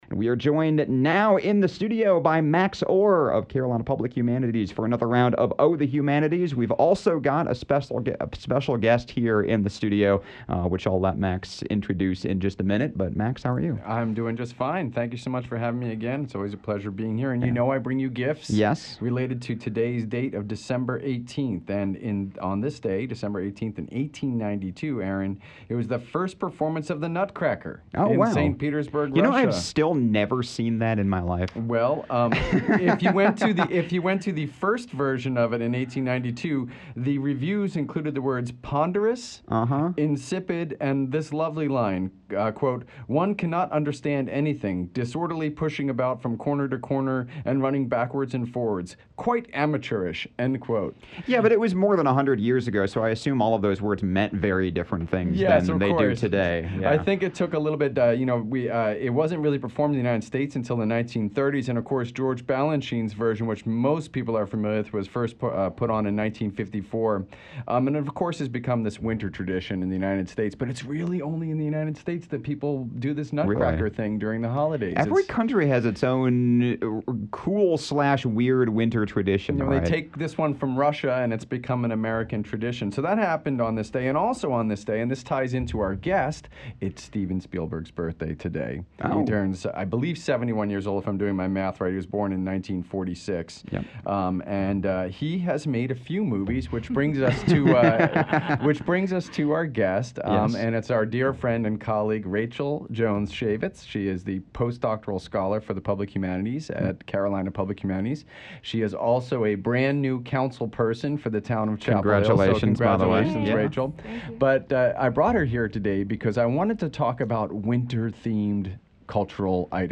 joined by film scholar (and new Chapel Hill Town Council member) Rachel Schaevitz